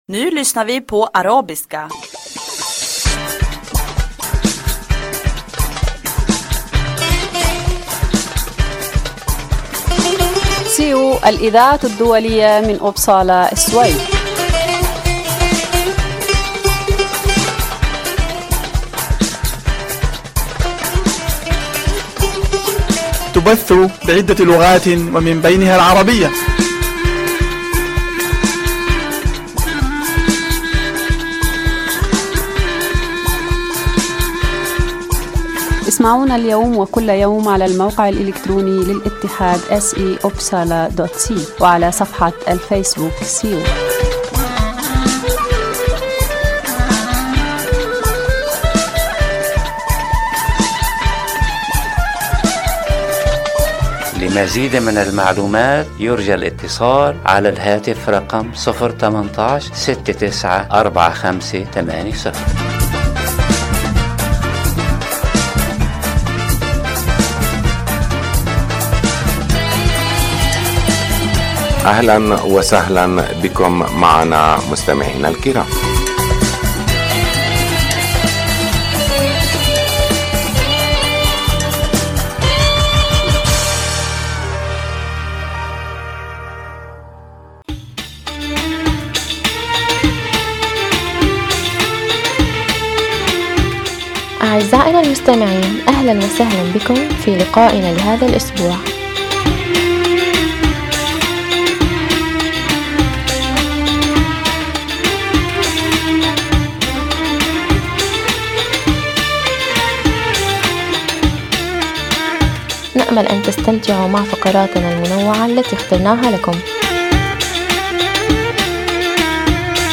يتضمن البرنامج أخبار من مدينة إبسالا و السويد تهم المهاجرين و برامج ترفيهية و مفيدة أخرى. برنامج هذا الأسبوع يتضمن أخبار الاتحاد السيو، من أخبارنا المحلية و مقتطفات من الصحف العربية و أخبار متنوعة و من الشعر و الموسيقى .